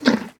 1.21.5 / assets / minecraft / sounds / entity / horse / eat3.ogg
eat3.ogg